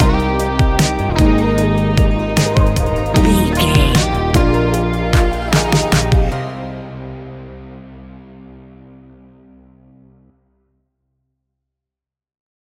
Ionian/Major
C♭
ambient
new age
downtempo
pads